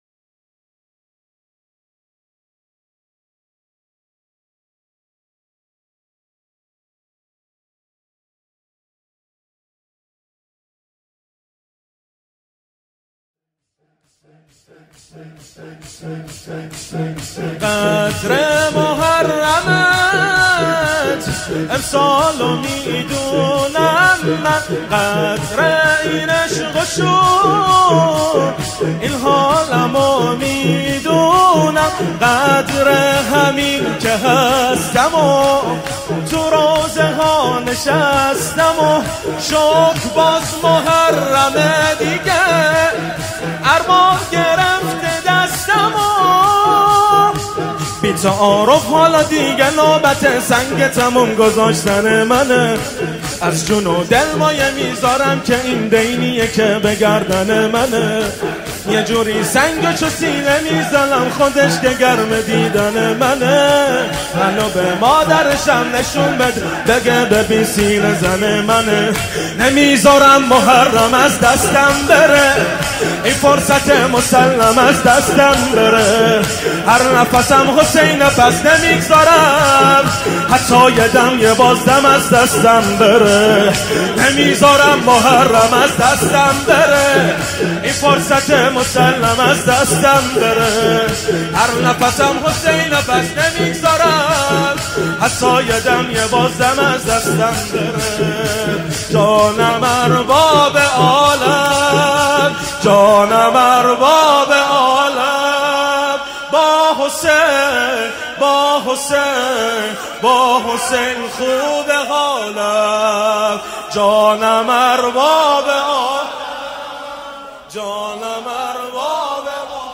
شور | قدر مُحرمت،امسالو میدونم من
مداحی
شب 1 محرم 1439 هجری قمری